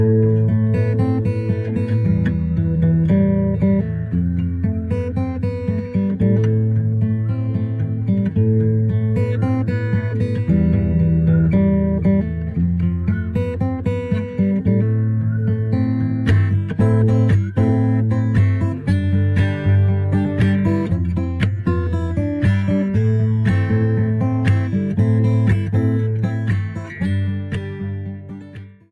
Guitar Ringtones